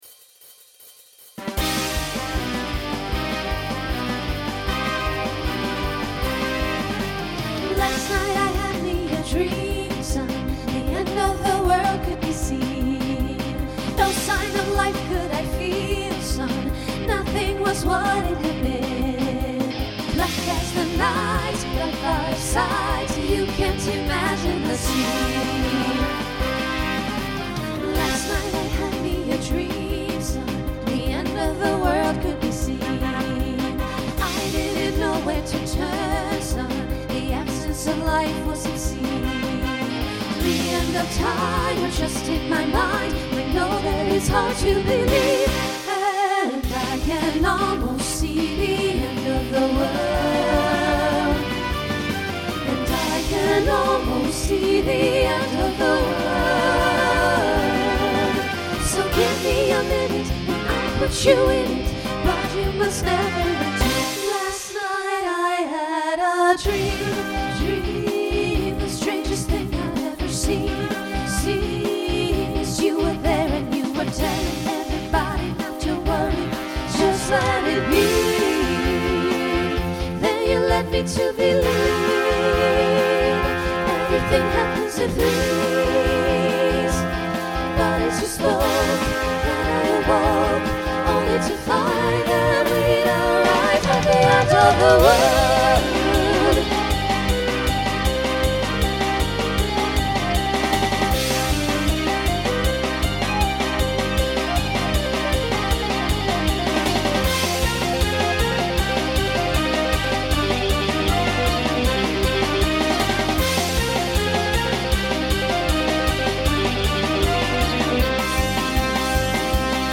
Voicing SSA Instrumental combo Genre Rock
Mid-tempo